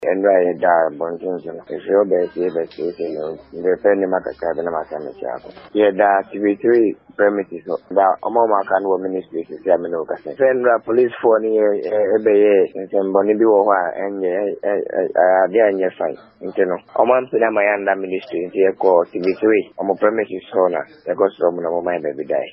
Listen to nurses